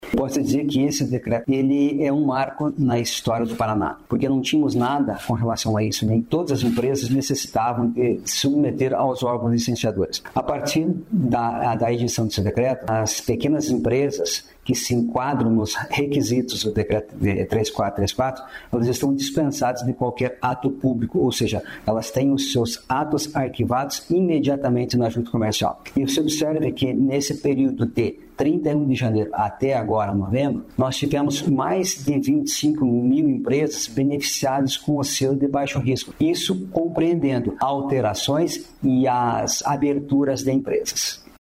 Sonora do presidente da Junta Comercial do Paraná, Marcos Rigoni, sobre o Selo do Baixo Risco